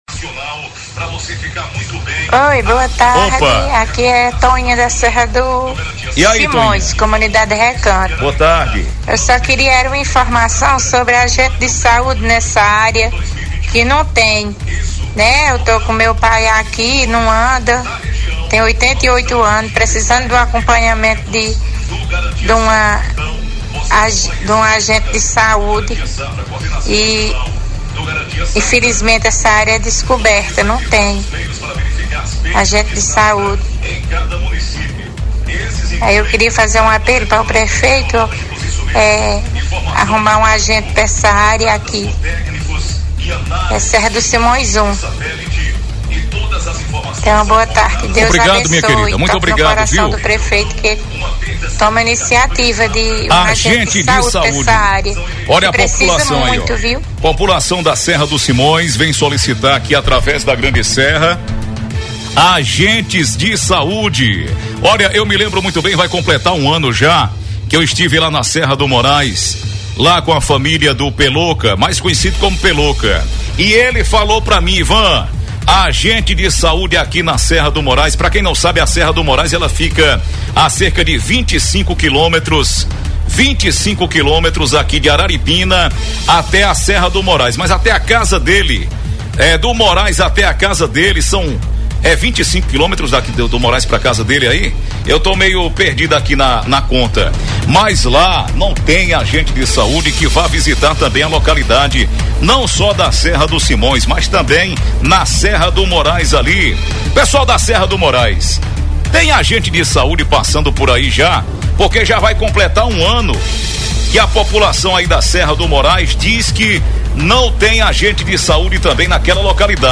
Moradores da zona rural de Araripina relataram, através da Rádio Grande Serra FM, no Programa Tribuna Livre, que vêm sofrendo com a falta de assistência de agentes de saúde.